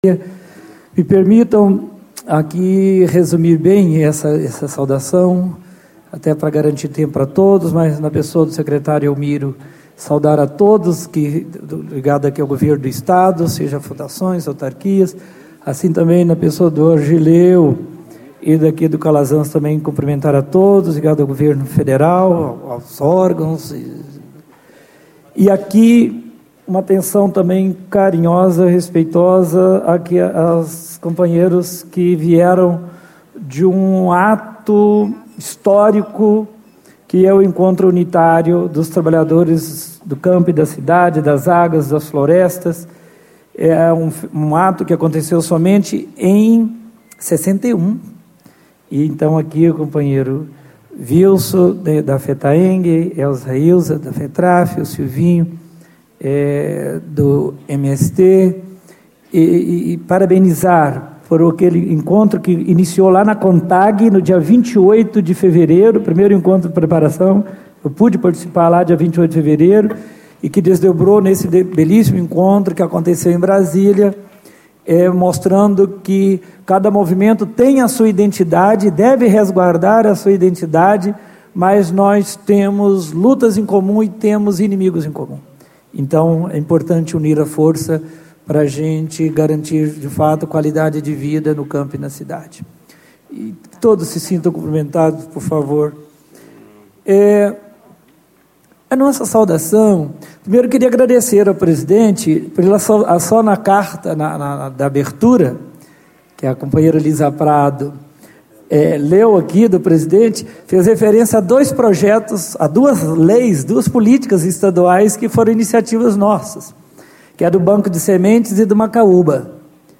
Ciclo de Debates Agricultura Familiar e Desenvolvimento Sustentável
Discursos e Palestras Ciclo de Debates Agricultura Familiar e Desenvolvimento Sustentável 29/08/2012 Escaneie o QR Code com o celular para conferir este audio Baixar áudio Deputado Federal, Padre João (PT)